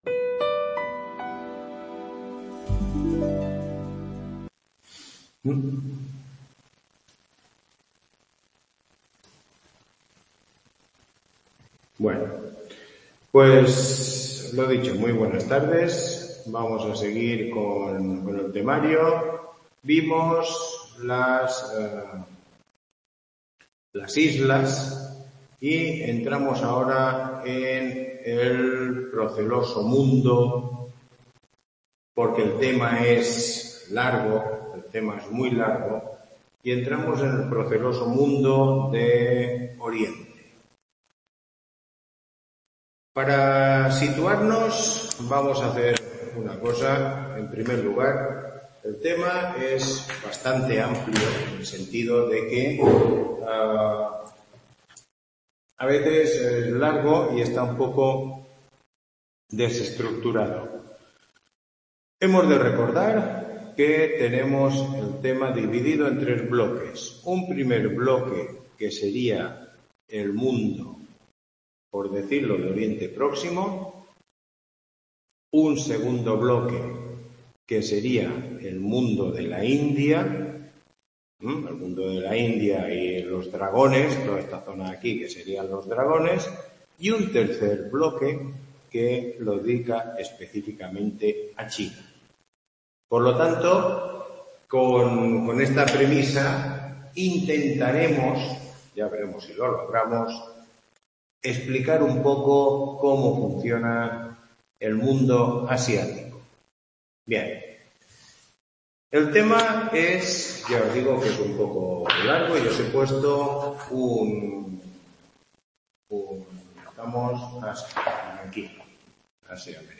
Tutoría 09